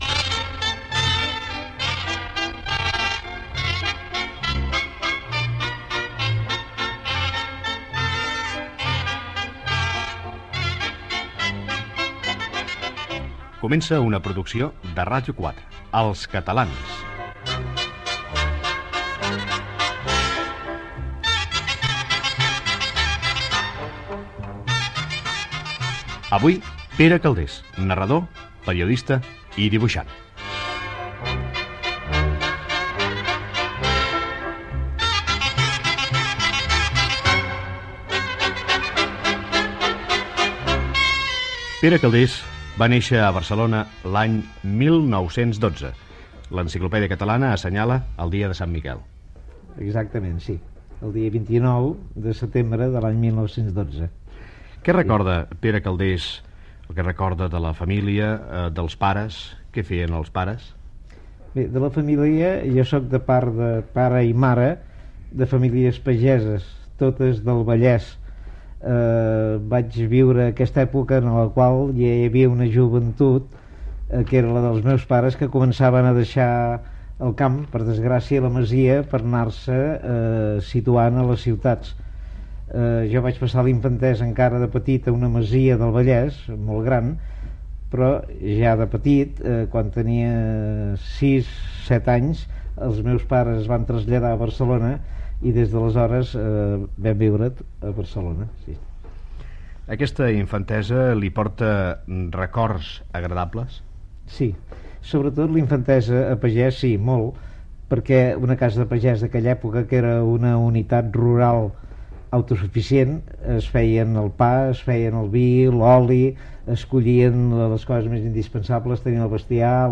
453ec296f1cb35c5590f478fce4e220c1a38df12.mp3 Títol Ràdio 4 Emissora Ràdio 4 Cadena RNE Titularitat Pública estatal Nom programa Els catalans Descripció Presentació i entrevista a l'escriptor Pere Calders. S'hi parla de la seva infància, família, estudis i de la Guerra Civil espanyola